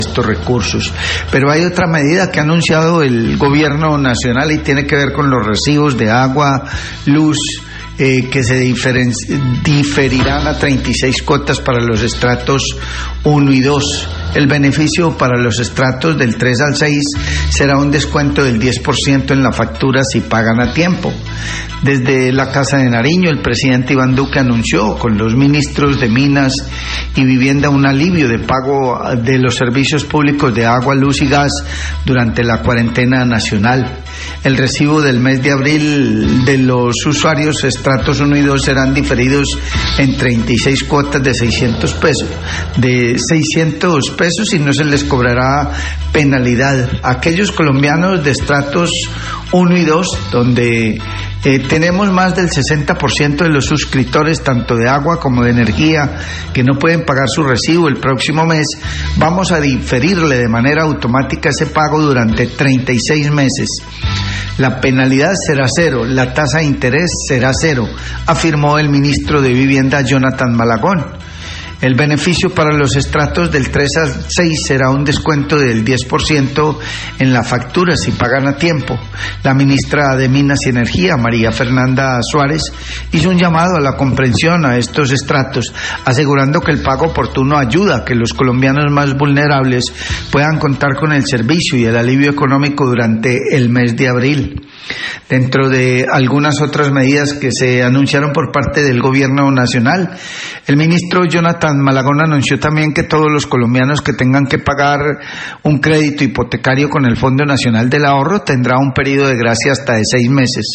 Radio
El presidente de la República, Iván Duque, habló sobre las medidas que se tomaran durante la cuarentena decretada en el país para enfrentar la pandemia del Covid-19.